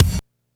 kick.wav